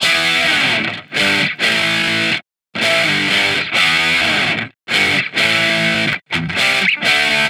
Guitar Licks 130BPM (16).wav